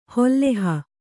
♪ holleha